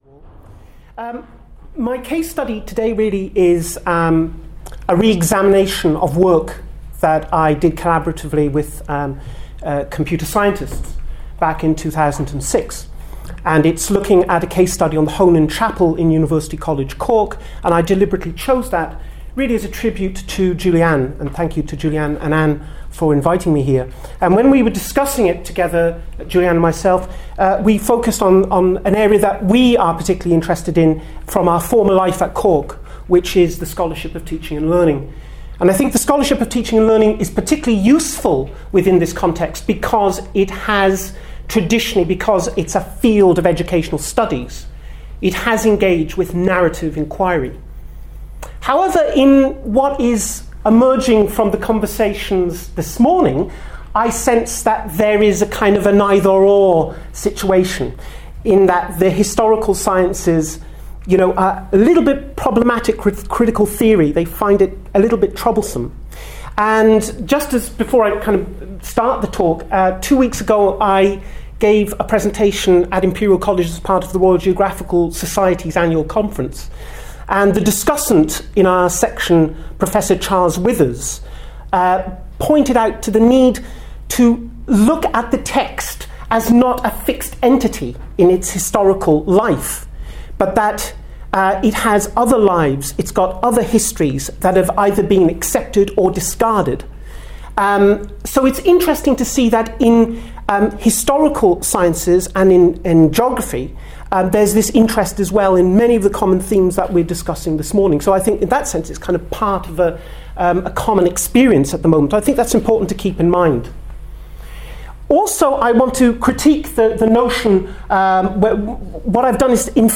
Extract from a presentation
at the Hidden Histories Symposium, September 2011, UCL.